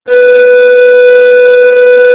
b-tune
snaar2b.amr